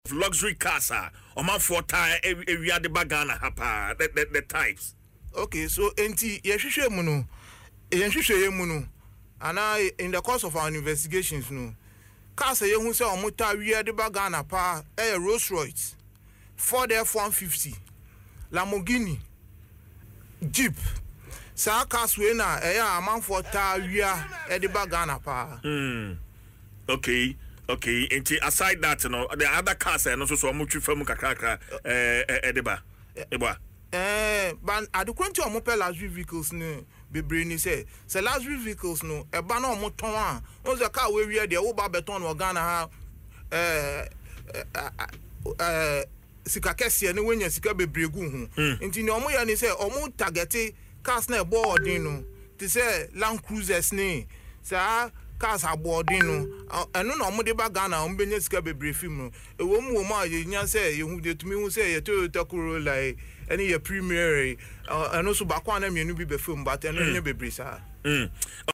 Speaking on Adom FM’s morning show Dwaso Nsem, he explained that criminals target high-end vehicles because they are more expensive and generate higher profits.